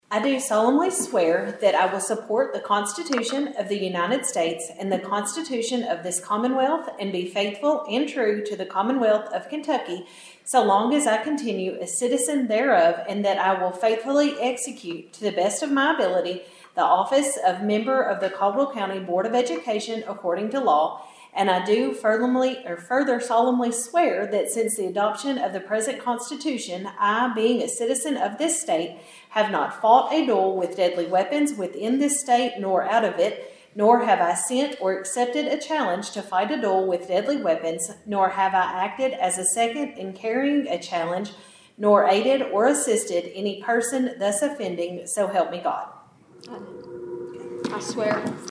A new member of the Caldwell County school board was sworn in at a special called working session Tuesday night.